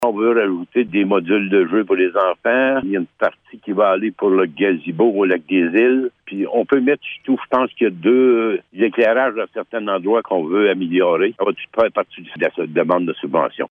Le maire de Blue Sea, Laurent Fortin, explique à quoi servira l’argent :